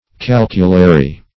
Search Result for " calculary" : The Collaborative International Dictionary of English v.0.48: Calculary \Cal"cu*la*ry\, a. [L. calculus a pebble, a calculus; cf calcularius pertaining to calculation.]
calculary.mp3